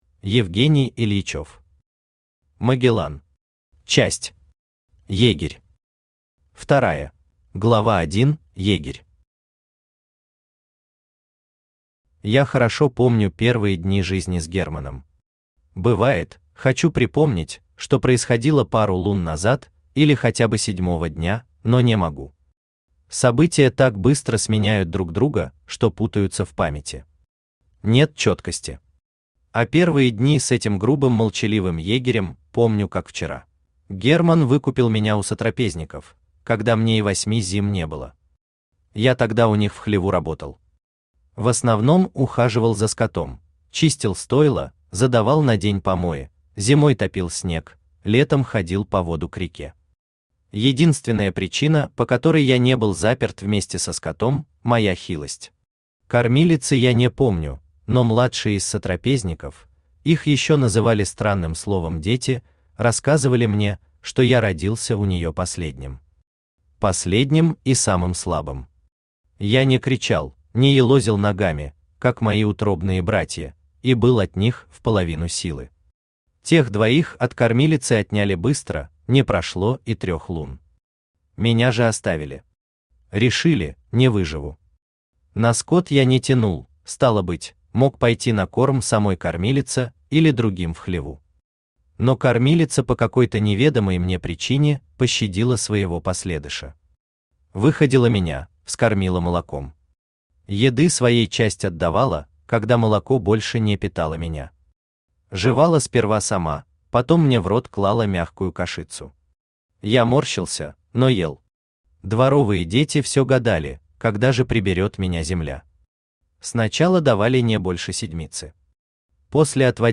Егерь Автор Евгений Юрьевич Ильичев Читает аудиокнигу Авточтец ЛитРес.